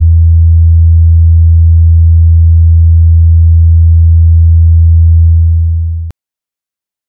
808s
Bass (2).wav